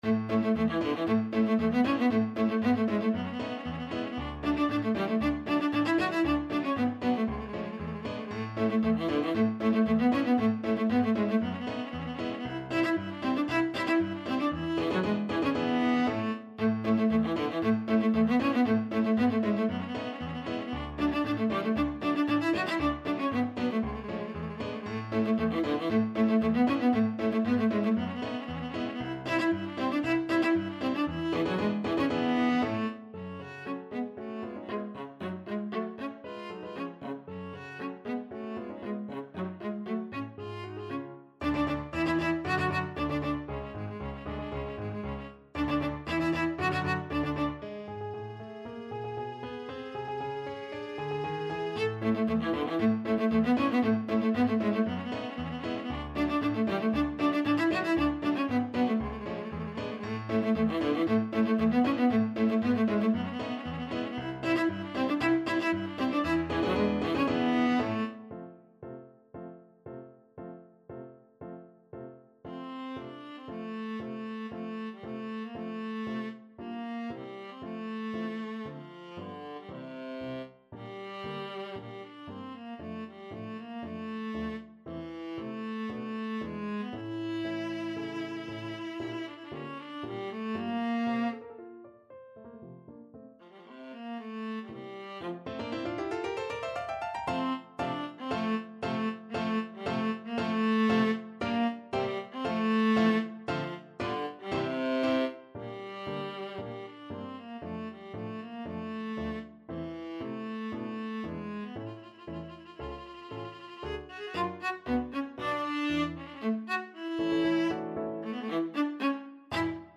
2/4 (View more 2/4 Music)
Classical (View more Classical Viola Music)